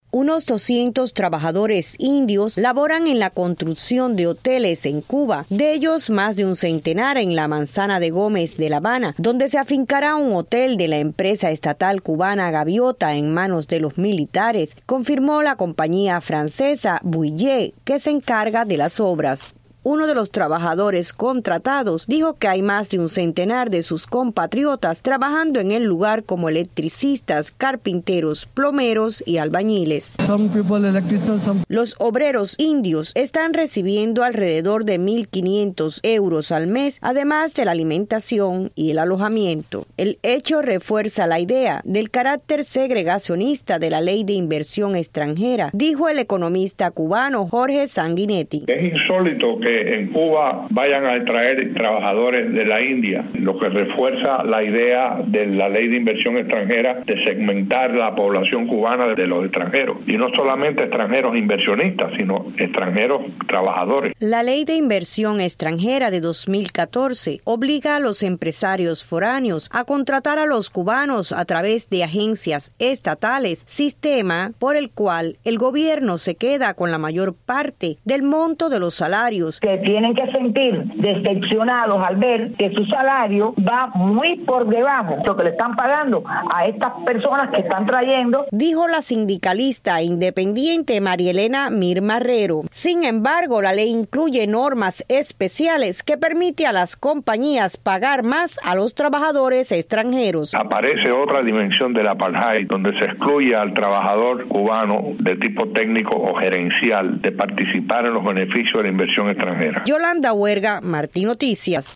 Reacciones de una sindicalista independiente y un experto en economía